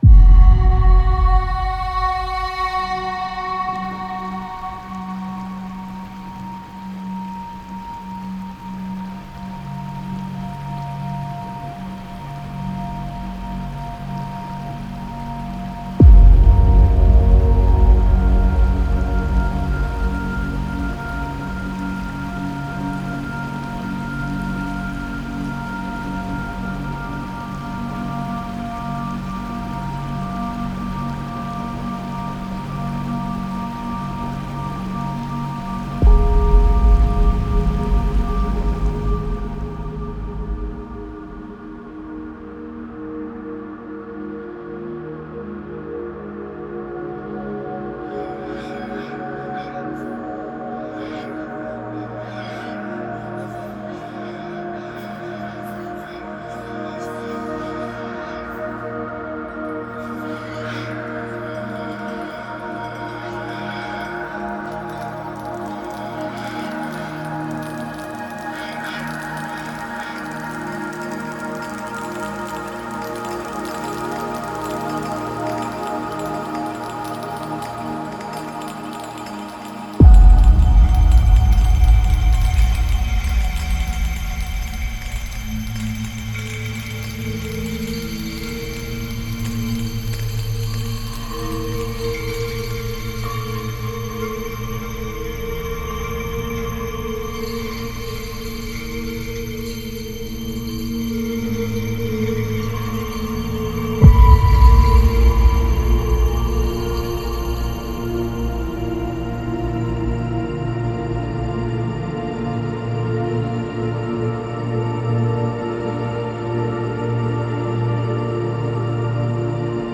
Genre:Cinematic
Cinescapes: Reveriaは、想像力を呼び覚まし、リスナーを超現実的な音響空間へと誘う、90種類の夢のようなシネマティックアンビエンスを豊富に収録したライブラリです。
レイヤー化されたテクスチャー、トーナルスウェル、感情的な共鳴から構築されており、あらゆるプロダクションに深み、温かみ、そして驚きをもたらします。
ライブラリには、浮遊感のあるDreamBeds、きらめくEchoTextures、豊かなFantasyWashesが収録され、それぞれ微細な動きと表現豊かなトーンで進化し、感情的なトランジション、没入型のアンダースコア、雰囲気あるストーリーテリングの制作に最適です。
90種類の感情的なシネマティックアンビエンス
感情的な深みを持つ夢のようで超現実的な浮遊テクスチャー